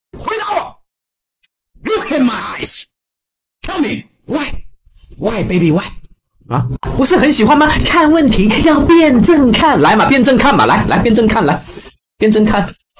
Generador de Voz de IA Enojada Realista
Texto a Voz
Narración Furiosa